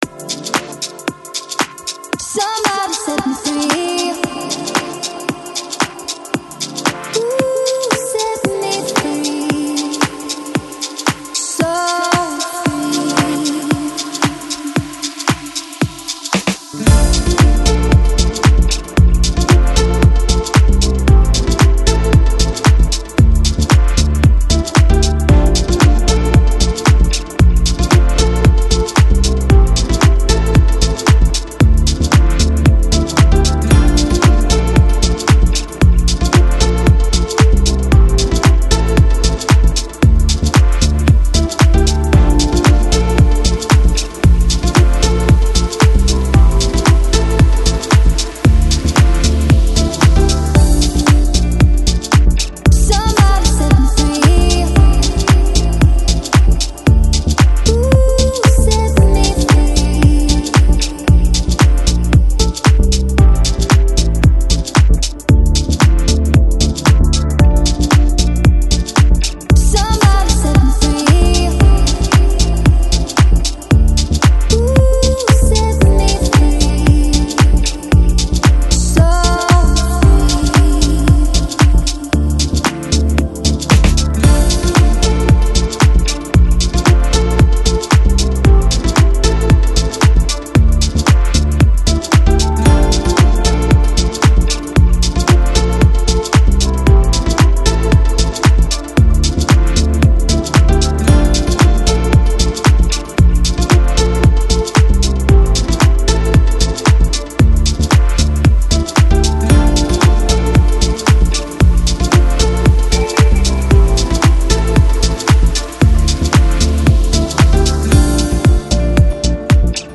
Жанр: Lounge, Chill Out, Downtempo